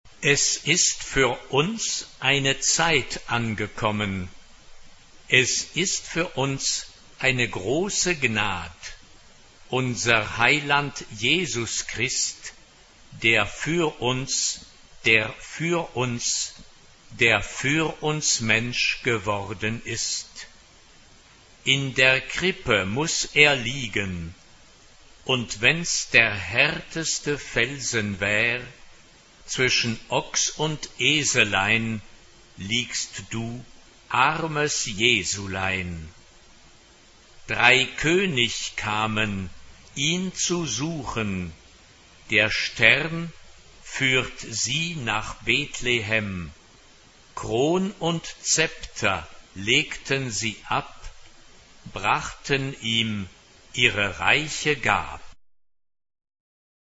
3 voces Coro femenino
Canción de Navidad.
Teclado
Tonalidad : sol mayor